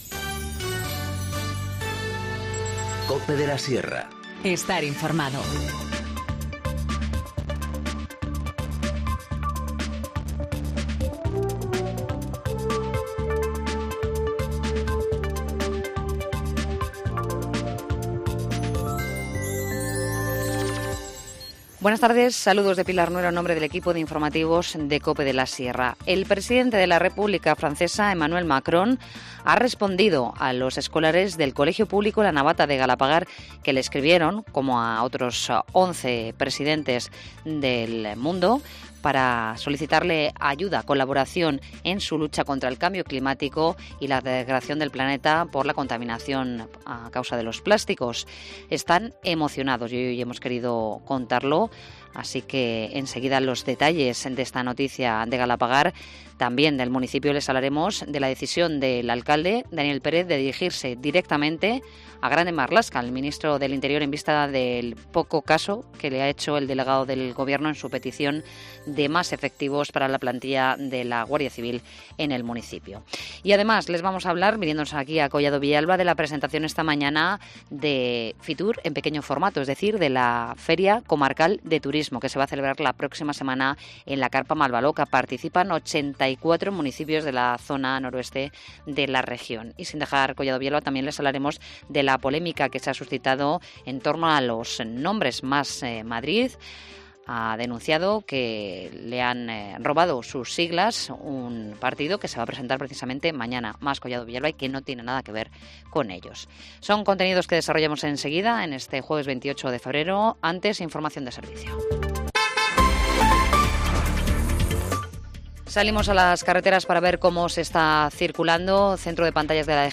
Informativo Mediodía 28 febrero-14:20h